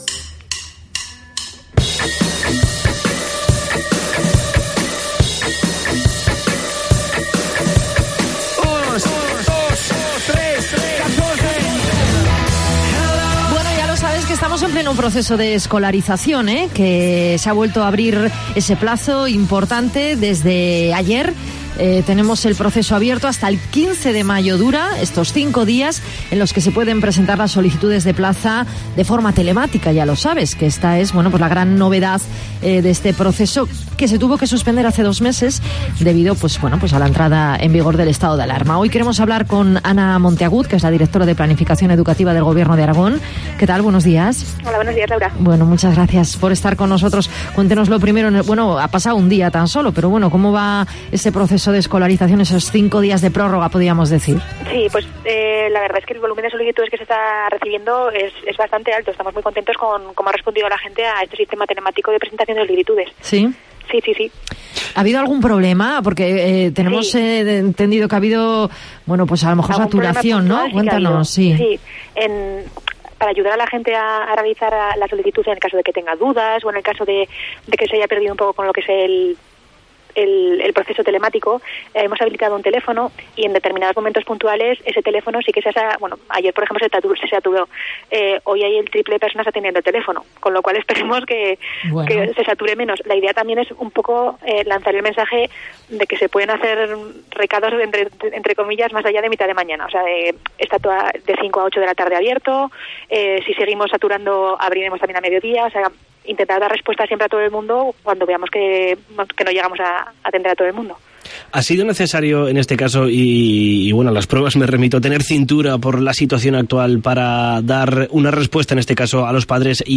Ana Montagud, Directora de Planificación de la DGA, explica cómo discurre este proceso en los micrófonos de Mediodía Cope
Entrevista a Ana Montagud, Directora General de Planificación de la DGA. 12-05-20